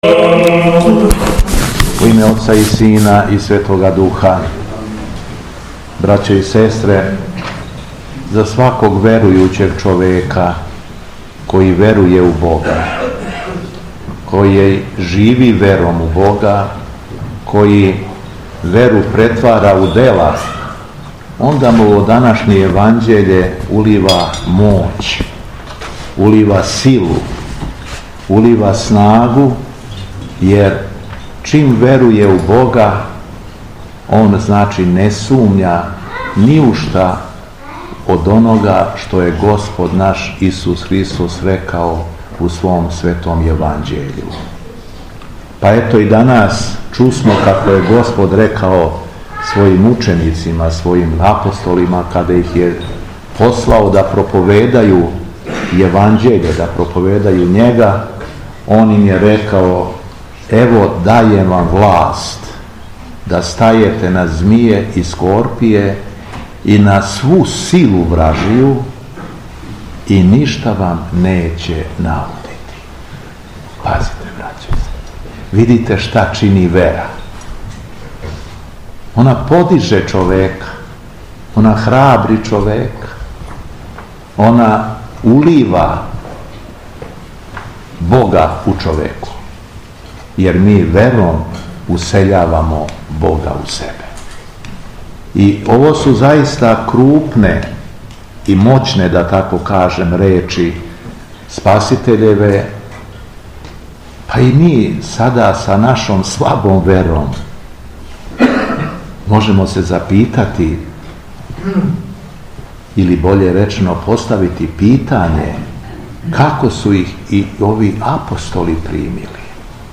У суботу 28. децембра 2024. године, када наша Света Црква прославља и празнује Светог свештеномученика Елевтерија и Преподобног Павла, Његово Високопреосвештенство Митрополит шумадијски Господин Јован служио је Свету Архијерејску Литургију у храму посвећеном Преподобном Симеону Столпнику у Дубони.
Беседа Његовог Високопреосвештенства Митрополита шумадијског г. Јована